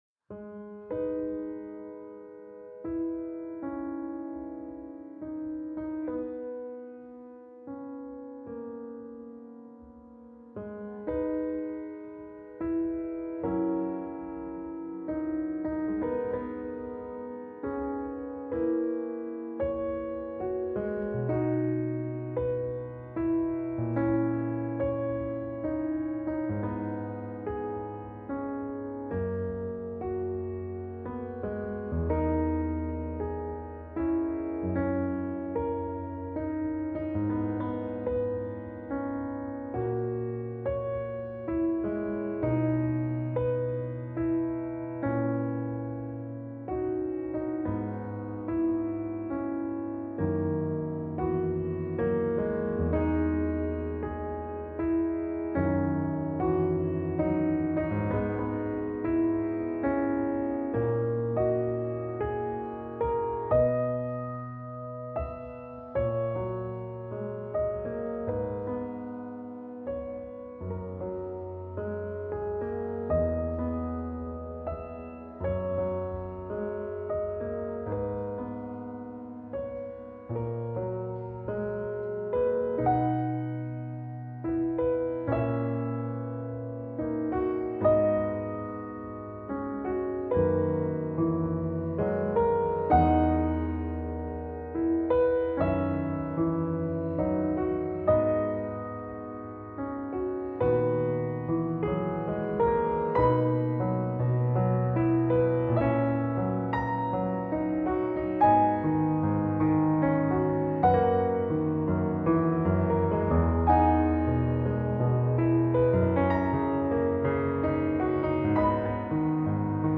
She sought the sound of nostalgia in the instrument.